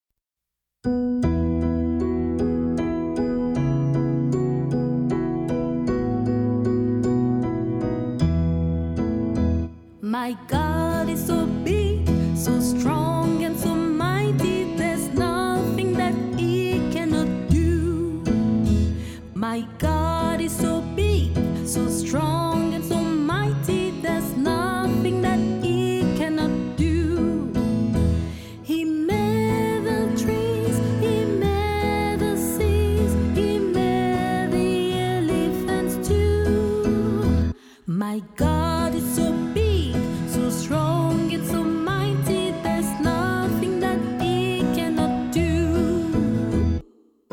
Song